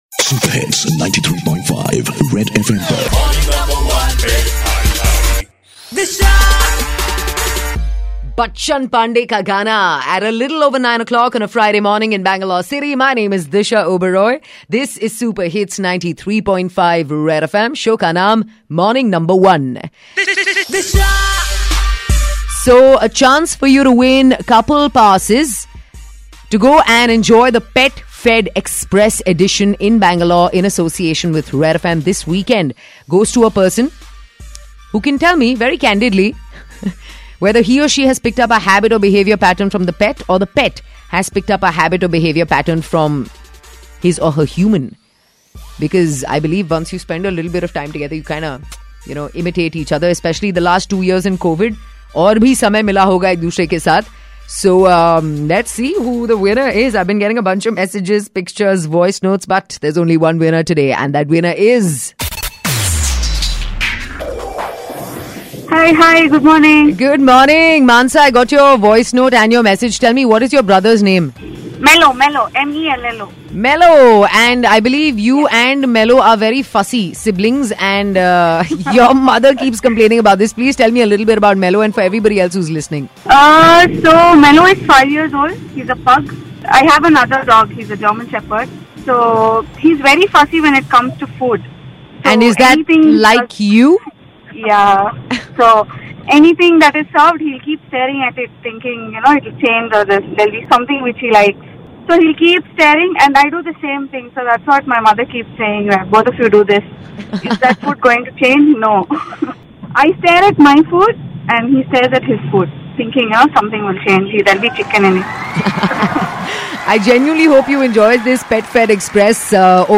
in conversation with winner of contest